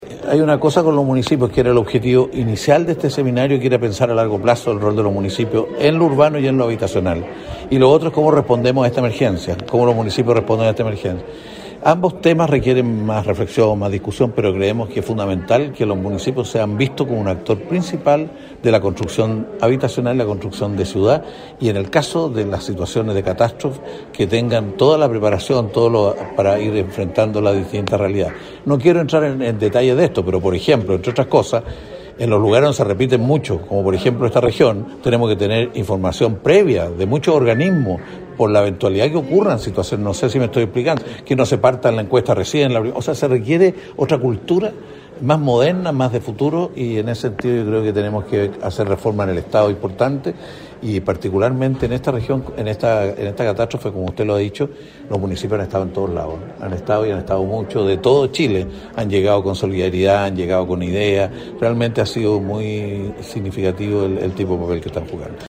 En tiempos de resiliencia y reconstrucción: Desafíos del desarrollo urbano y rural para el Chile del 2050 es el título del seminario organizado por la Asociación Chilena de Municipalidades y que se desarrollará en la Universidad del Bío-Bío hasta este viernes.